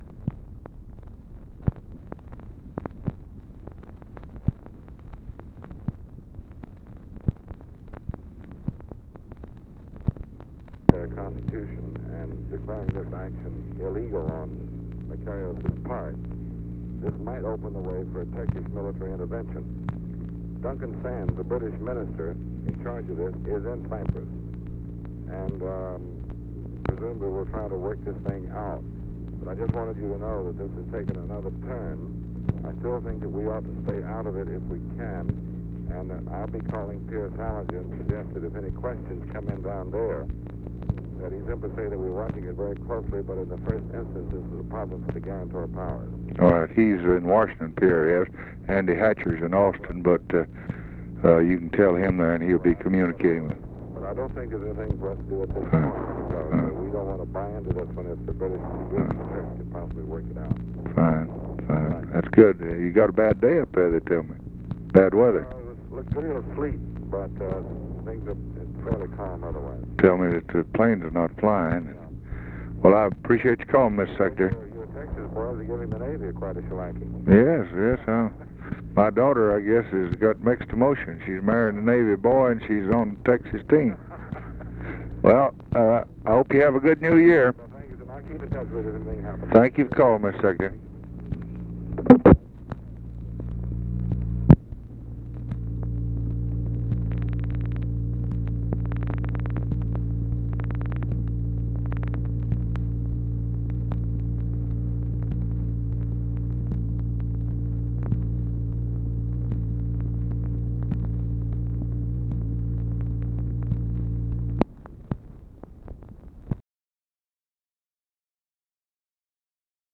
Conversation with DEAN RUSK, January 1, 1964
Secret White House Tapes